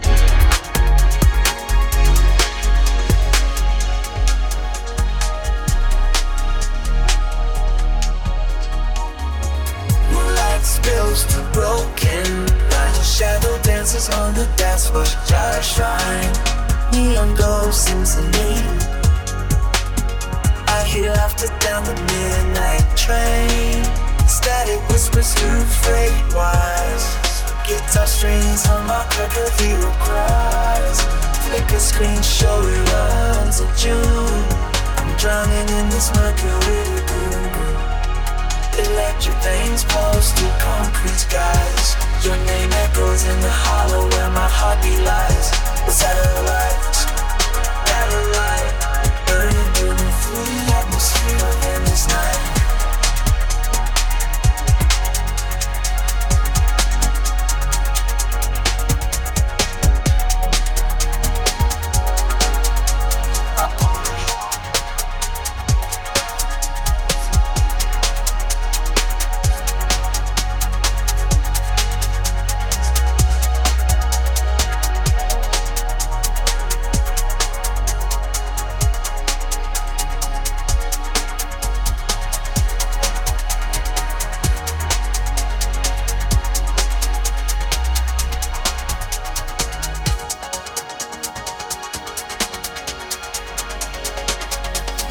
lyrics-to-music lyrics-to-song music-generation singing-voice-generation